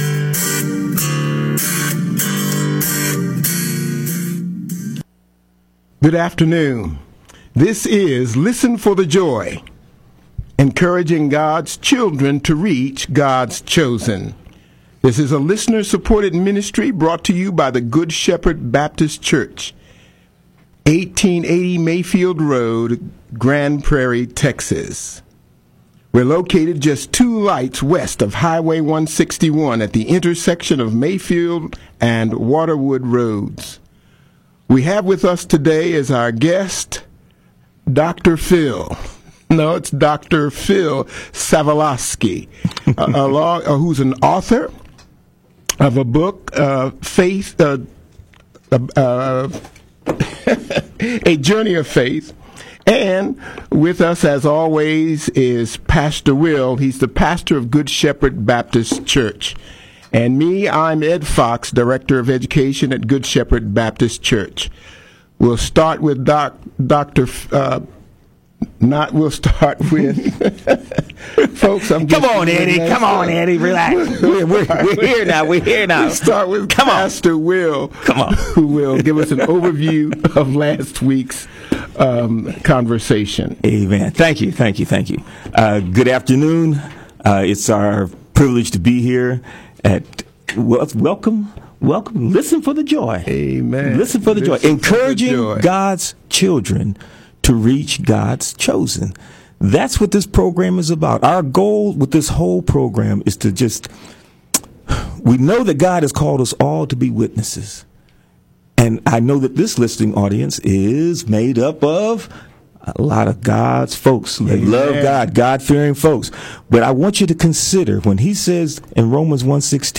This will be a Talk, Testimony, and Teaching Program with Live Call-ins. There will be interviews with Believers both, Jew and Gentile.